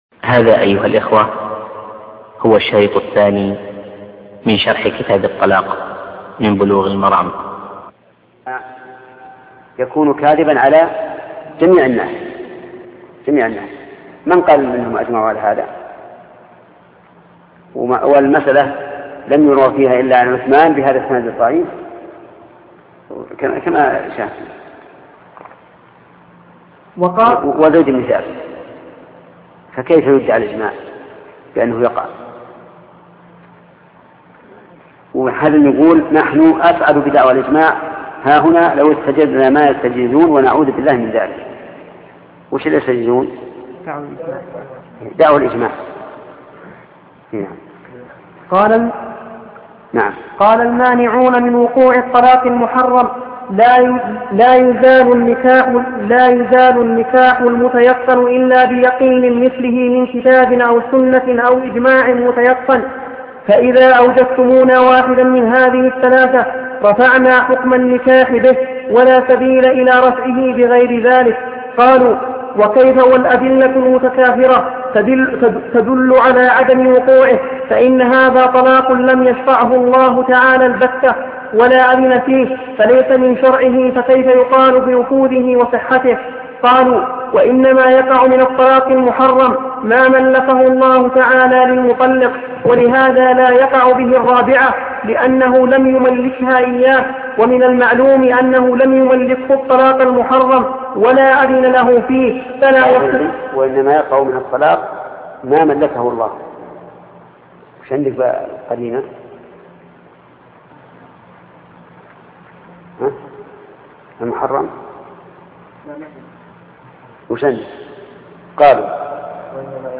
بلوغ المرام من أدلة الأحكام شرح الشيخ محمد بن صالح العثيمين الدرس 209